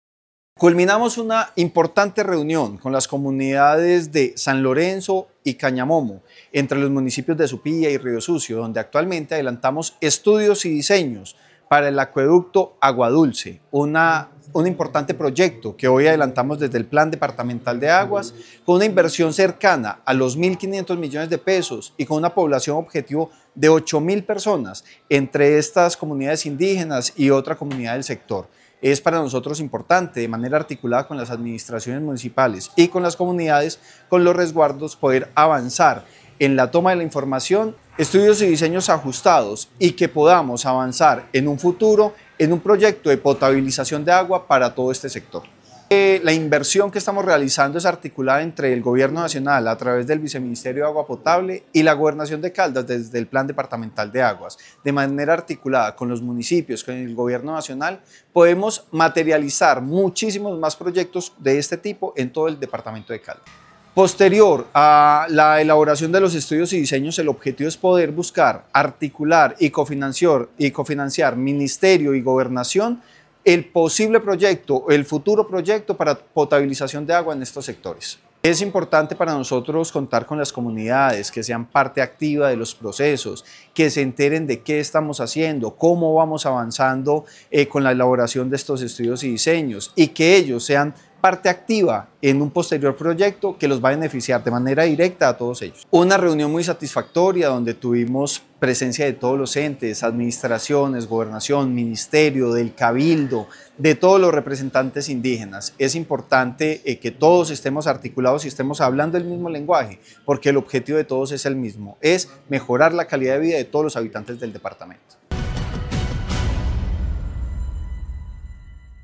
Secretario de Vivienda de Caldas, Francisco Vélez Quiroga.